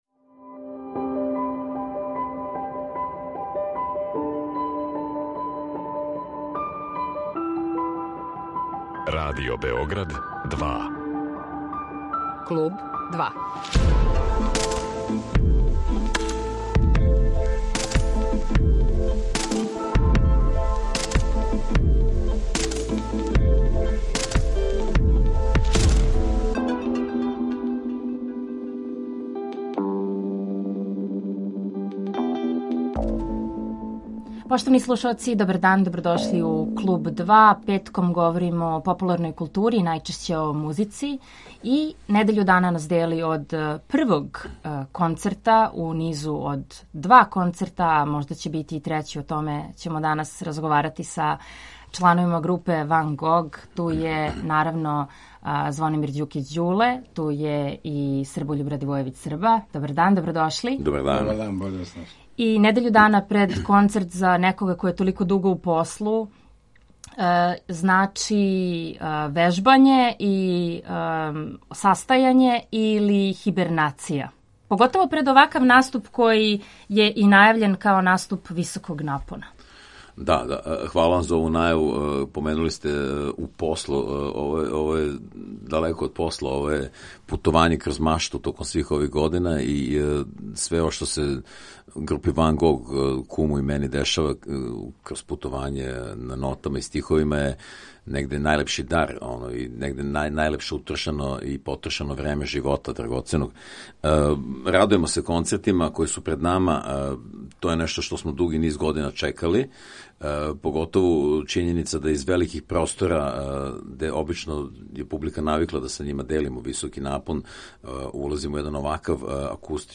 Гост емисије је Звонимир Ђукић Ђуле